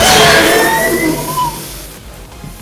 Jolly 3: Chapter 1 Jumpscare Sound
jolly-3-chapter-1-jumpscare-sound.mp3